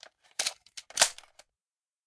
magReload.ogg